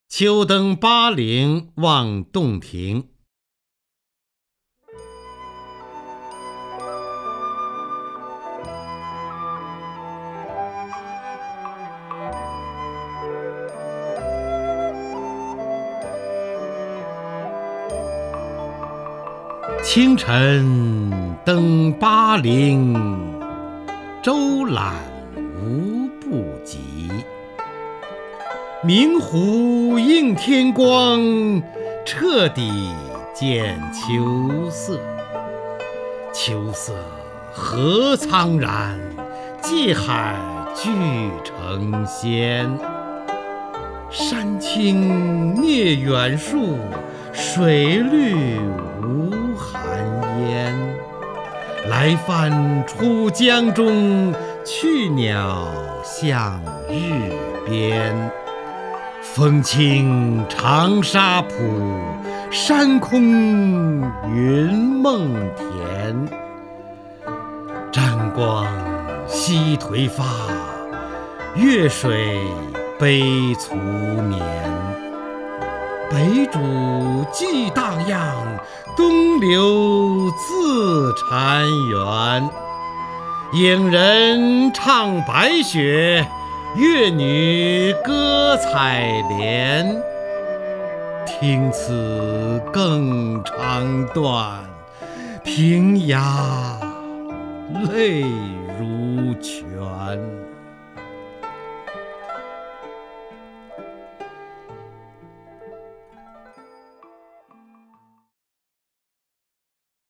方明朗诵：《秋登巴陵望洞庭》(（唐）李白)　/ （唐）李白
名家朗诵欣赏 方明 目录